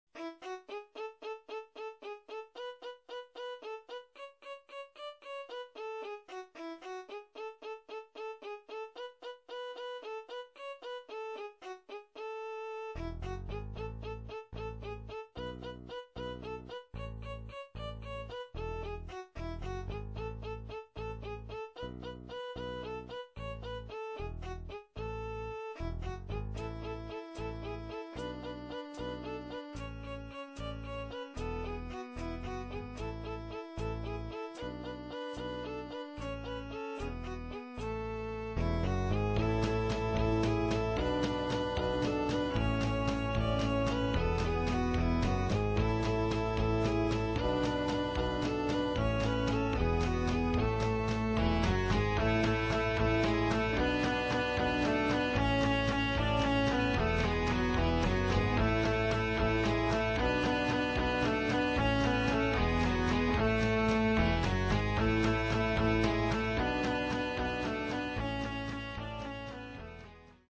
Everything is made in MIDI which gives a thinner sound.
[instr.]